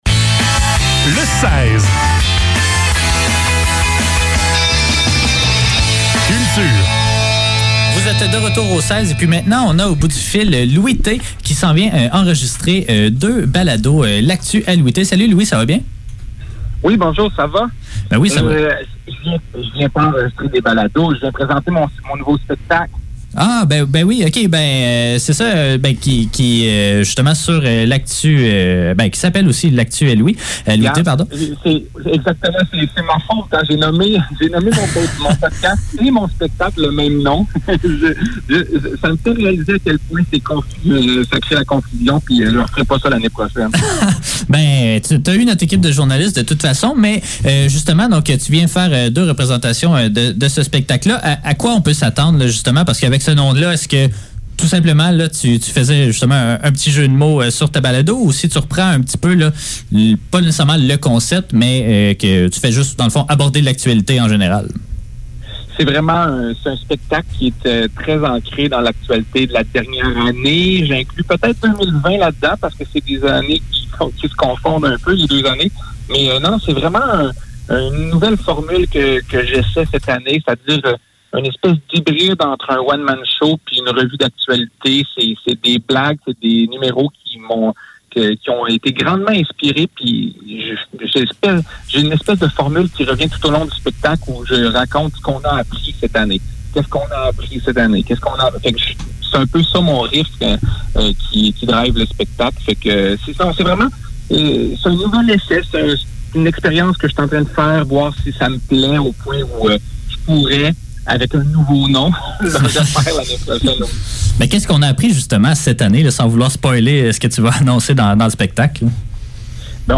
Le seize - Entrevue avec Louis T - 6 octobre 2021